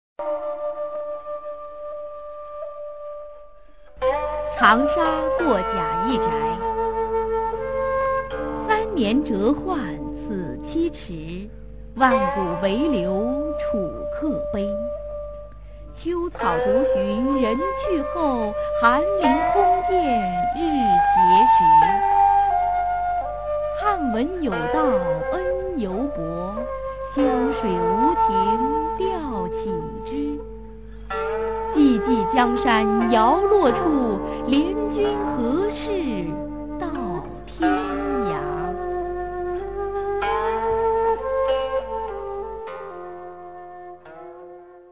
[隋唐诗词诵读]刘长卿-长沙过贾谊宅a 配乐诗朗诵